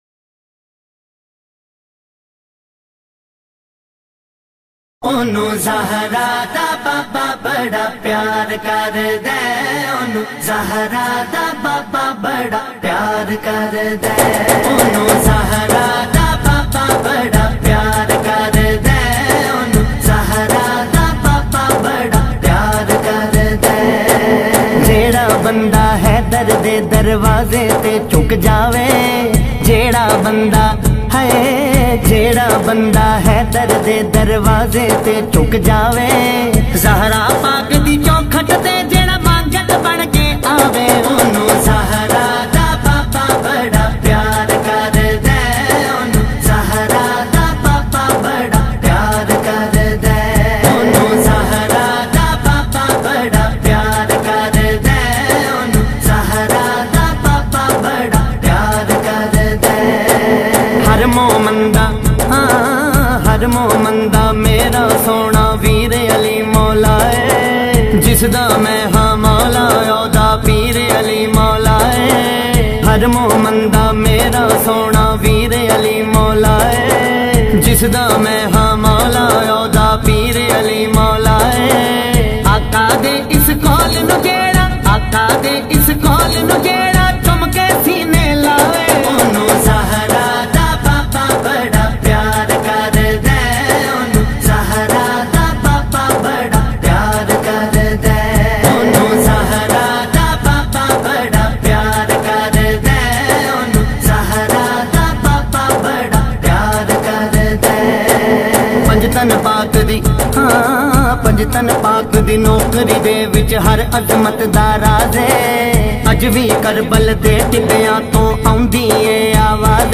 manqbat
in a Heart-Touching Voice